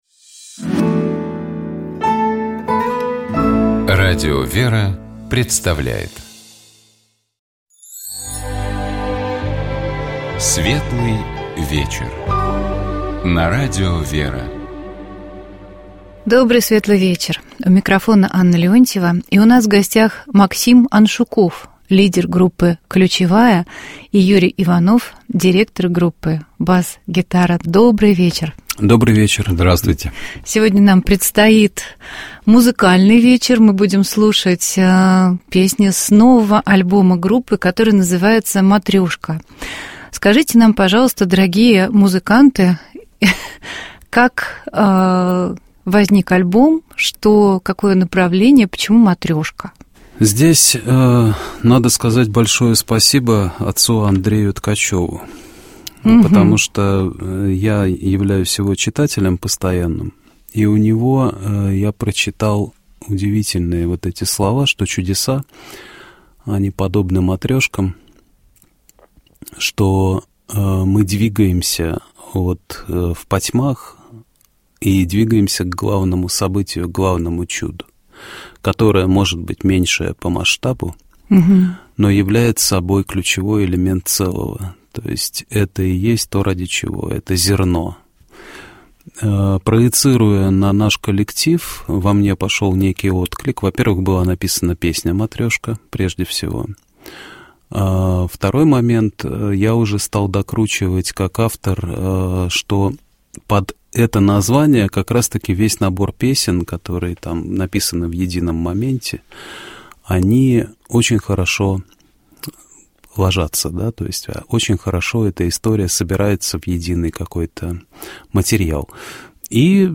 Всенощное Бдение. 28 сентября (вечер 27 сентября) 2025г.